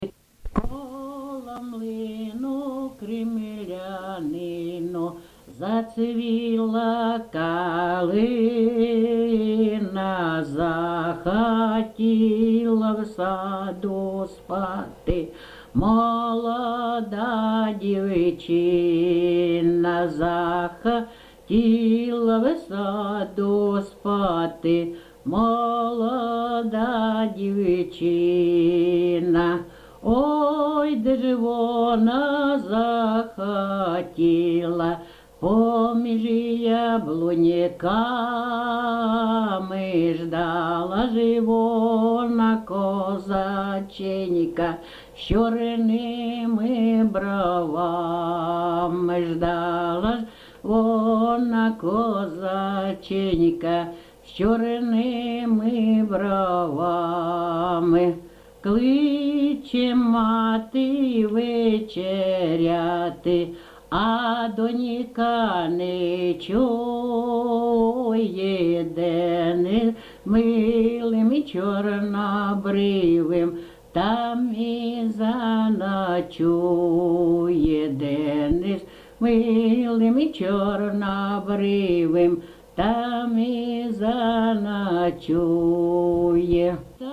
ЖанрПісні з особистого та родинного життя
Місце записус. Некременне, Олександрівський (Краматорський) район, Донецька обл., Україна, Слобожанщина